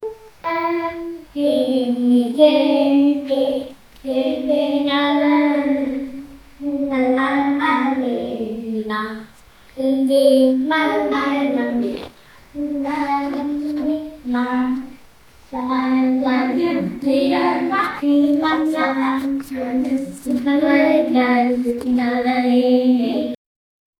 Chorus Sound Effects - Free AI Generator & Downloads
-children-singing-togethe-sqdipjj6.wav